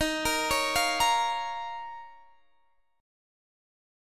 D#7sus2 Chord (page 2)
Listen to D#7sus2 strummed